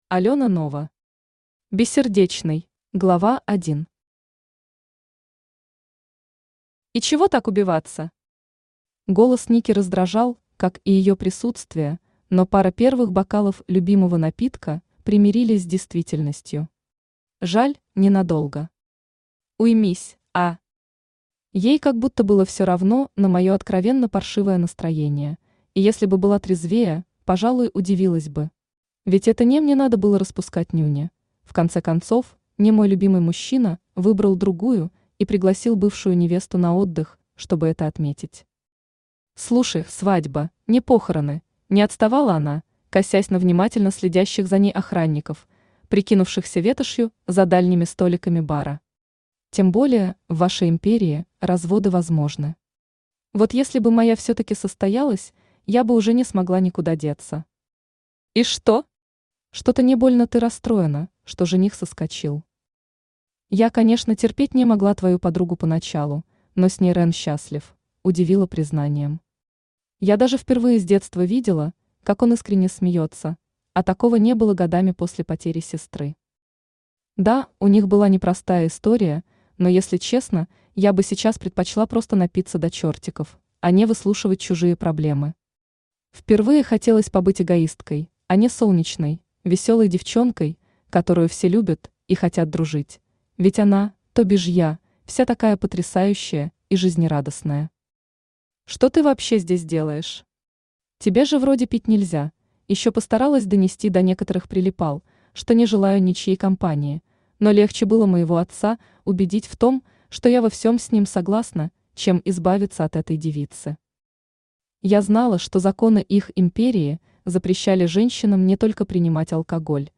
Аудиокнига Бессердечный | Библиотека аудиокниг
Aудиокнига Бессердечный Автор Алёна Нова Читает аудиокнигу Авточтец ЛитРес.